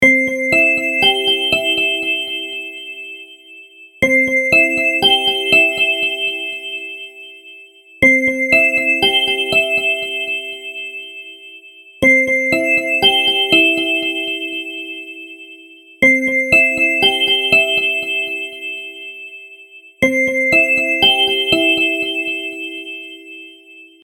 phone-ringtone-car-358562.mp3